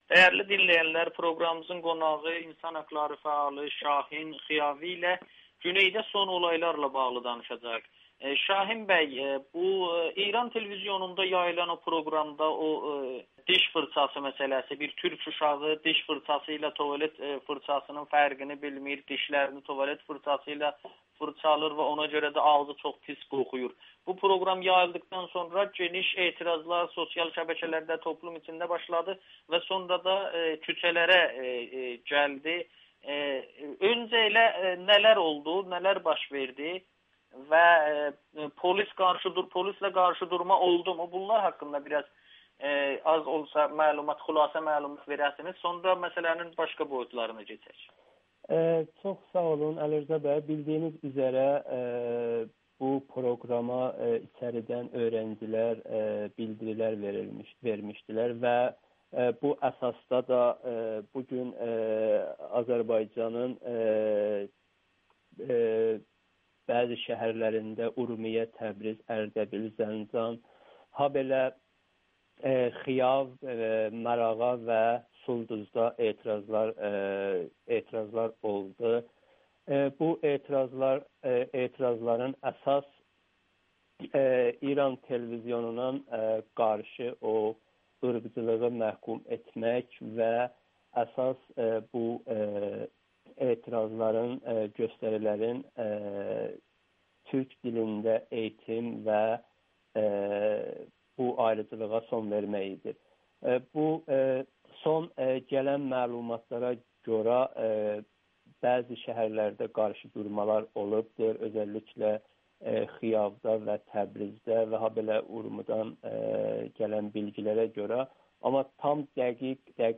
Etirazçıların tələbi türklərə qarşı ayrıseçkiliyə son qoyulmasıdır [Audio-Müsahibə]